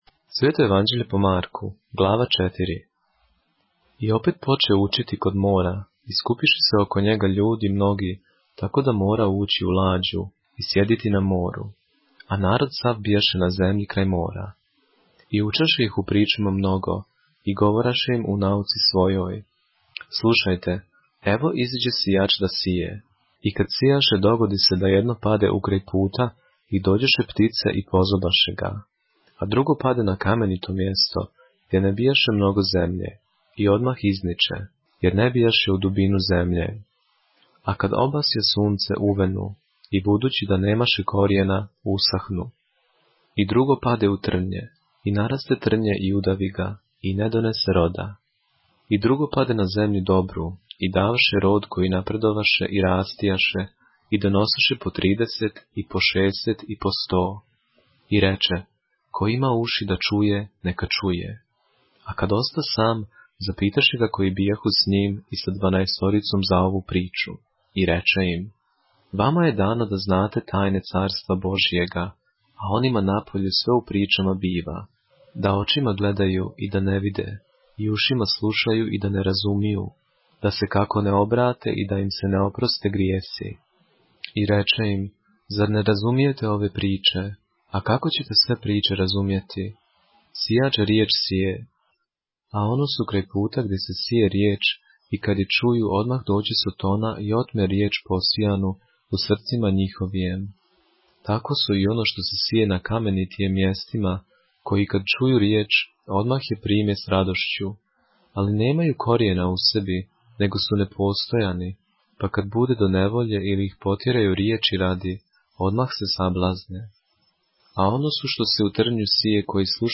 поглавље српске Библије - са аудио нарације - Mark, chapter 4 of the Holy Bible in the Serbian language